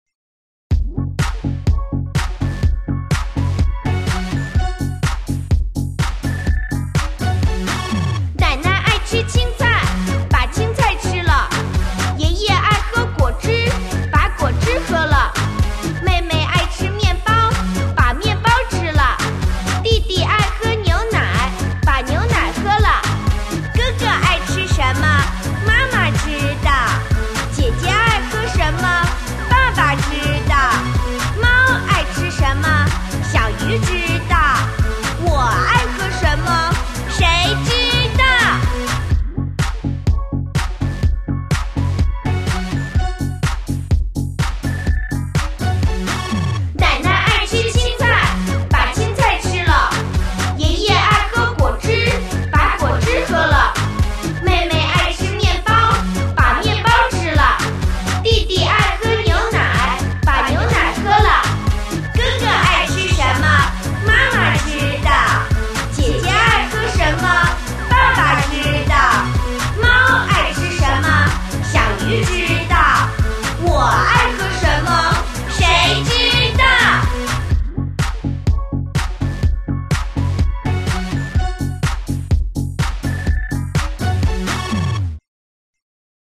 Cùng hát nhé!
Bây giờ, chúng ta cùng ôn lại bài thông qua bài hát vui nhộn dưới đây nhé!